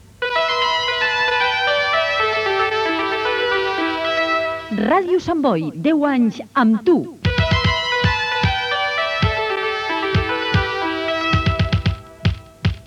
Indicatiu dels 10 anys de l'emissora.